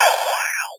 Gamer World Open Hat 6.wav